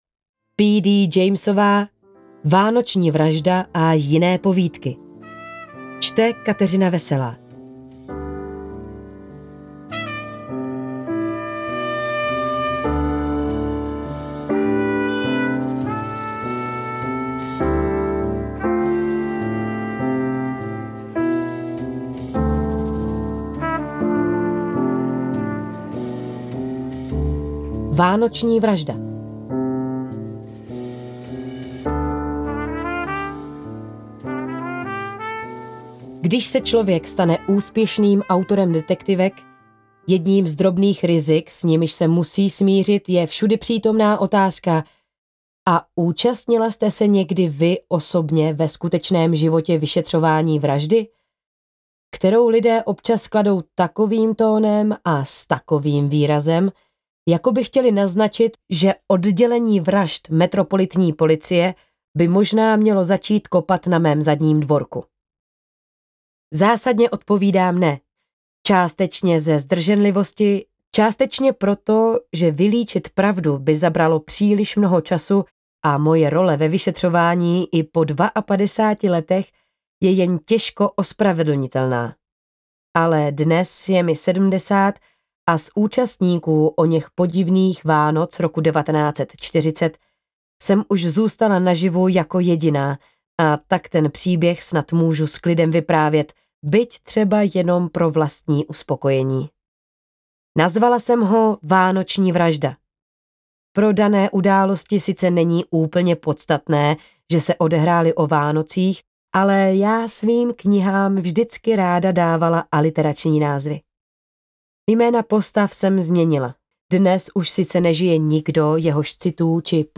AudioKniha ke stažení, 4 x mp3, délka 3 hod. 39 min., velikost 301,1 MB, česky